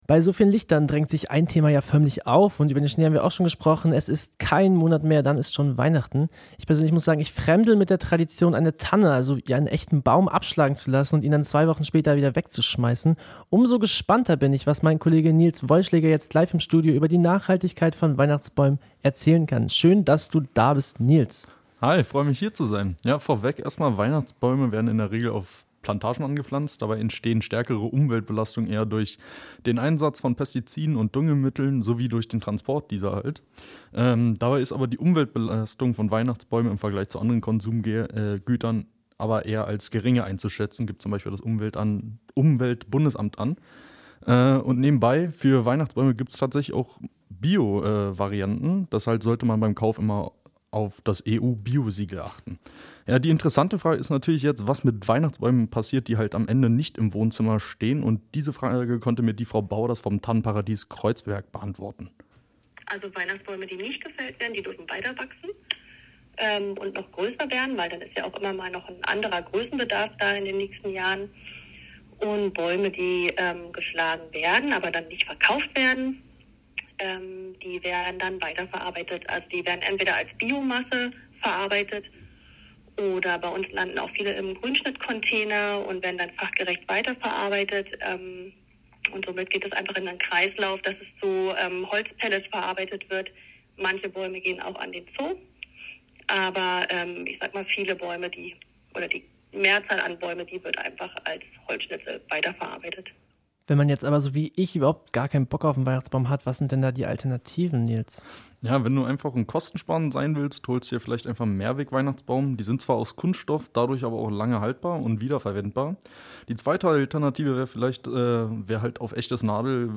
Kollegengespraech-Weihnachtsbaum.mp3